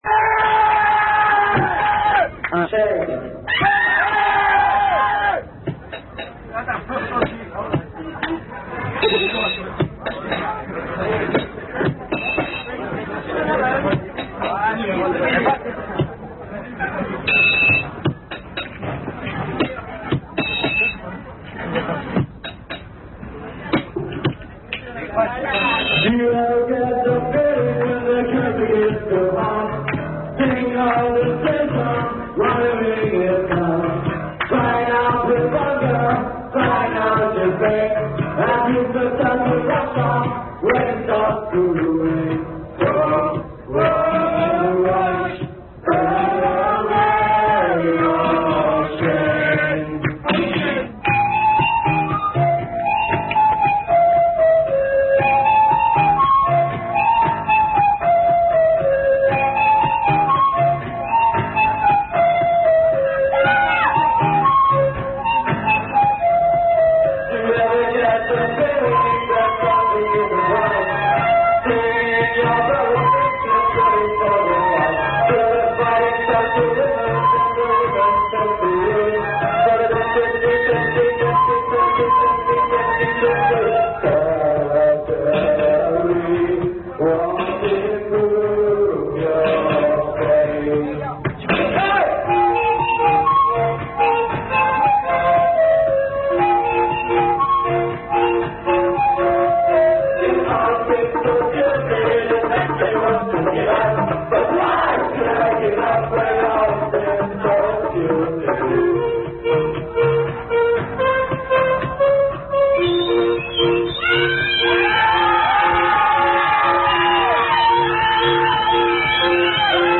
9.05.91.- France, Dunkerque